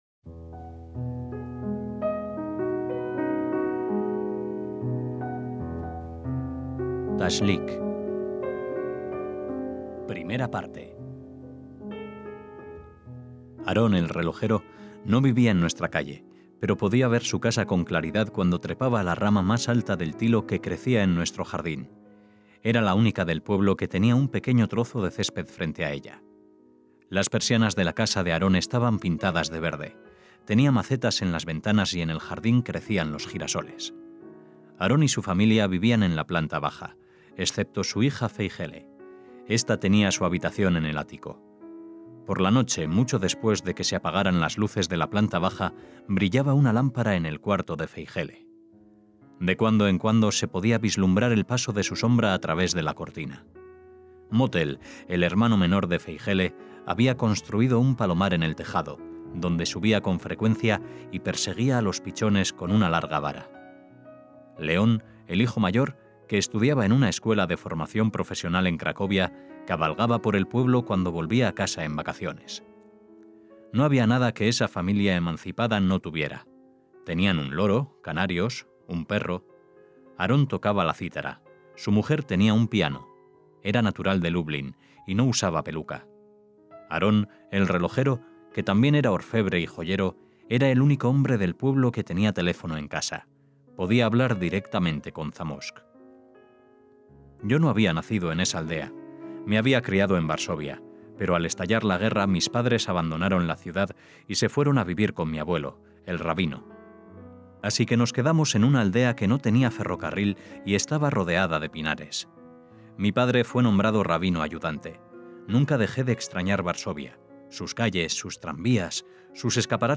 CUENTOS PARA NIÑOS DE ISAAC BASHEVIS SINGER – Continúa la lectura de breves historias infantiles del Nóbel de Literatura 1978, Isaac Bashevis Singer (1902 – 1991), hijo y nieto de rabinos que vivió en el barrio judío de Varsovia hasta 1935, año en que emigró a Estados Unidos. Hoy contamos la primera parte de una historia romántica, ambientada en los tiempos de Rosh Hashaná, el inicio del nuevo año judío.